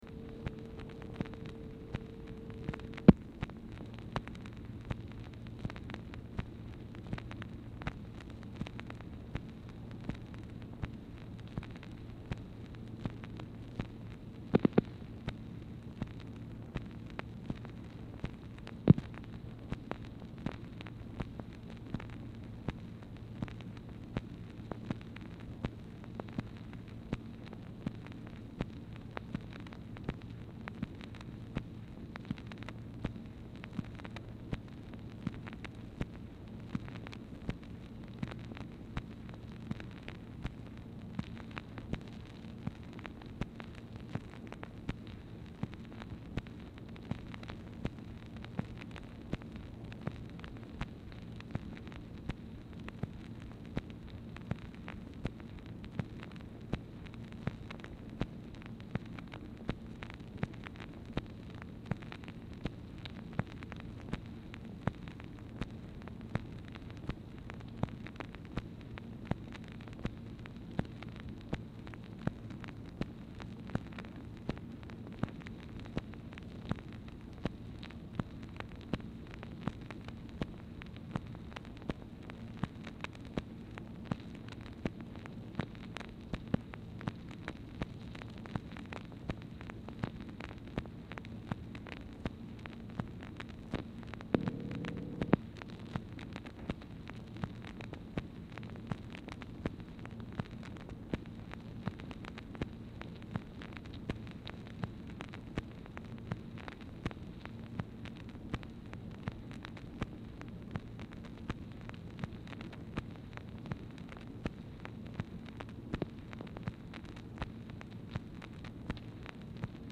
Telephone conversation # 3133, sound recording, MACHINE NOISE, 4/25/1964, time unknown | Discover LBJ
Format Dictation belt